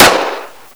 assets/pc/nzp/sounds/weapons/colt/shoot.wav at 9ea766f1c2ff1baf68fe27859b7e5b52b329afea
shoot.wav